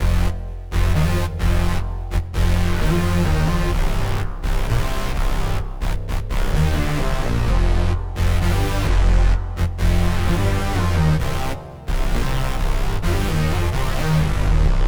01 bass.wav